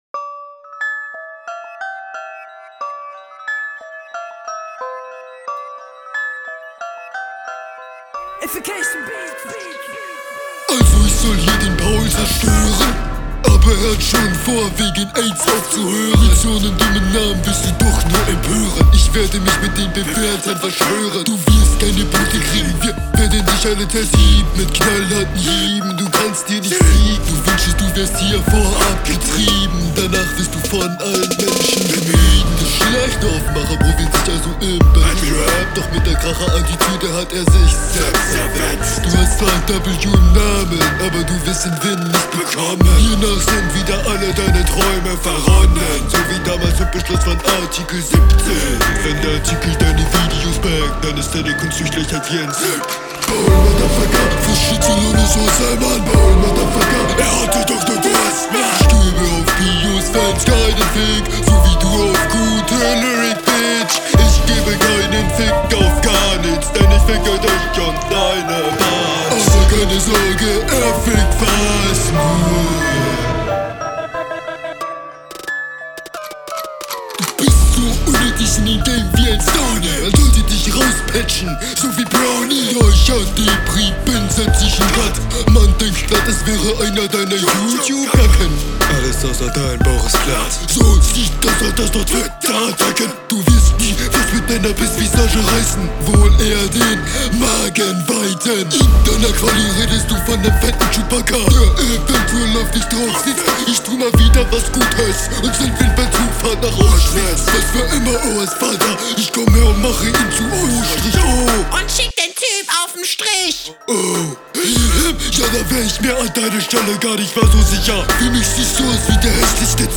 Flow: Der Beat ist n epischer Kopfnicker. Den Kopfnickervibe bekomm ich beim Flow nicht.